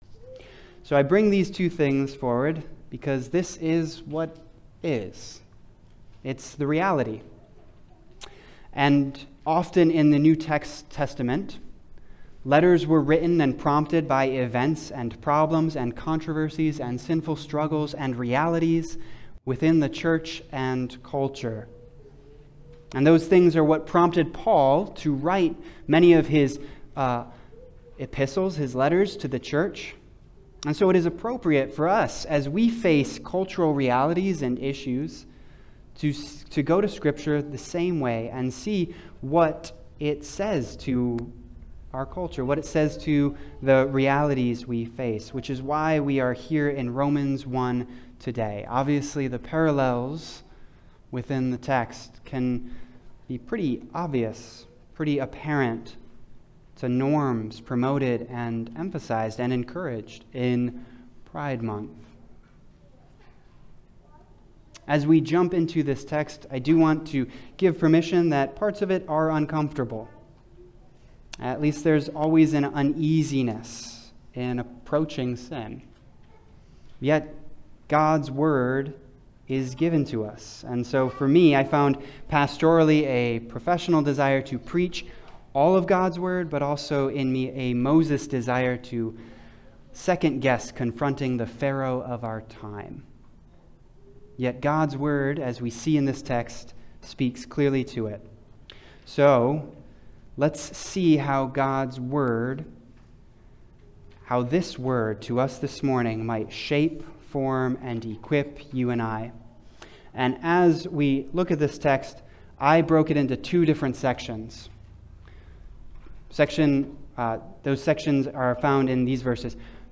The recording of this sermon was started a few minutes late and the reading of the scripture was not recorded. Please read Romans 1:16-32 prior to listening.
Service Type: Sunday Service